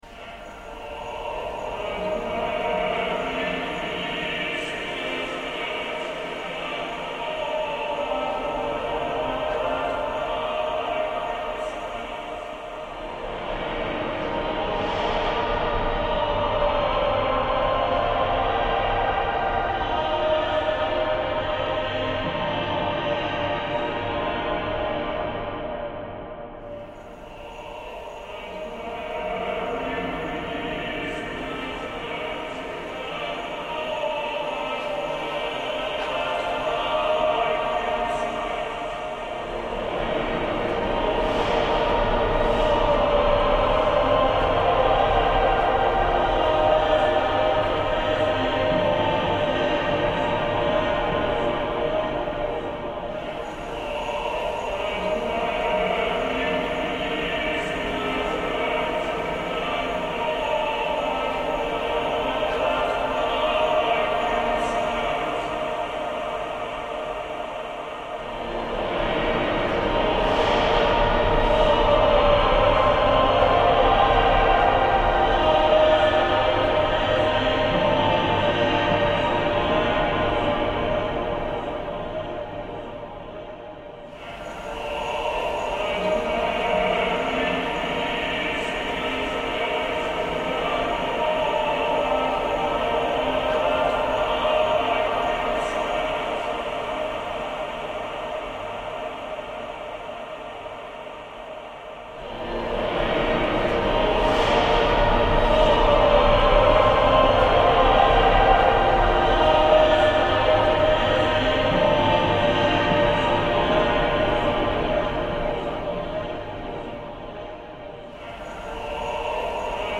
Christmas music in a church in Vicenza